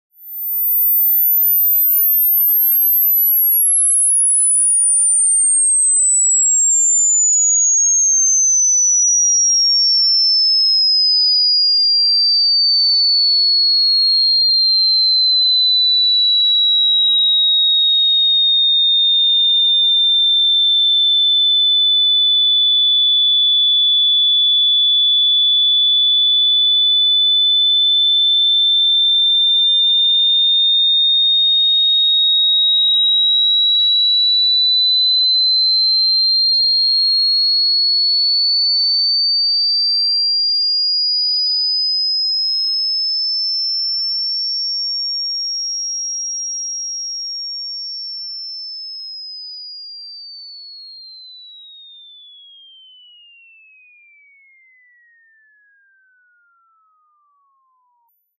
Звуки ультразвука
Ультразвуковой отпугиватель для кота